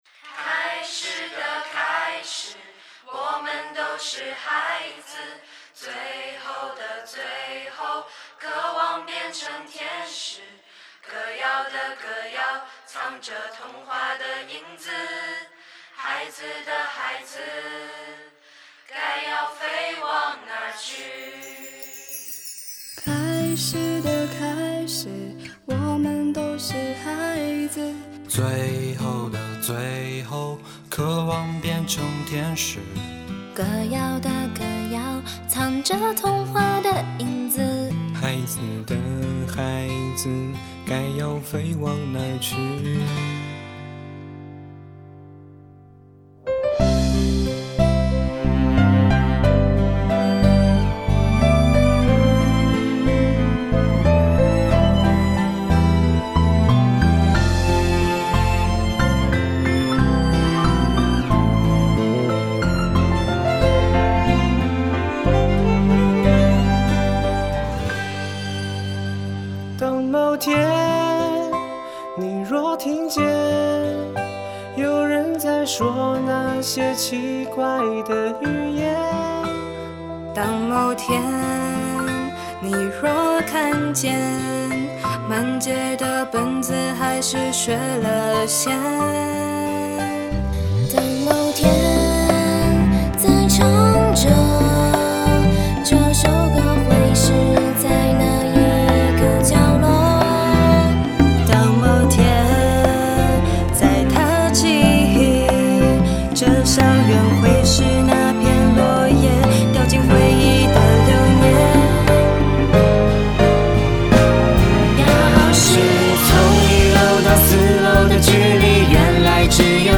“开始的开始，我们都是孩子；最后的最后，渴望变成天使；歌谣的歌谣，藏着童话的影子；孩子的孩子，该要飞往哪去……”MV以联唱方式开场，十几位风华正茂的男女少年头戴耳麦轮番亮相，明星味十足。很难相信，那低沉高亢不一却同样打动人心的歌声，是来自一群终日与外语打交道的十七八岁高三生。